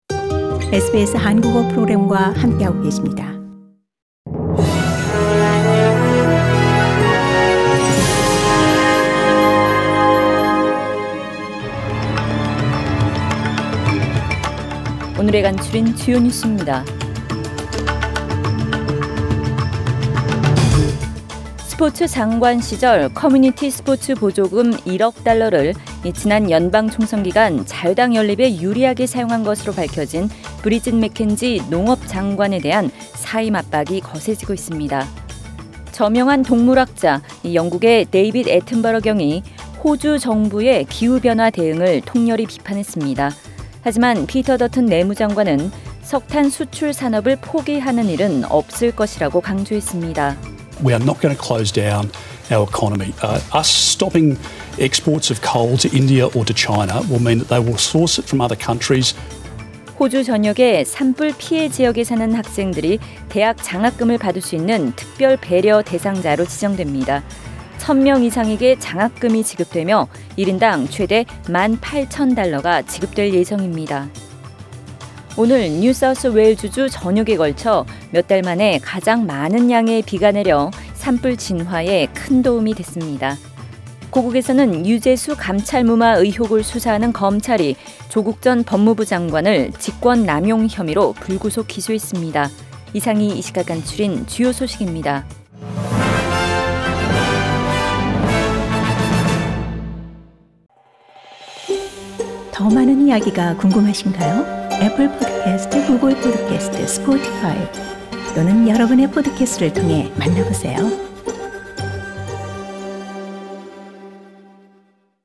Major stories from SBS Korean News on Friday 17 January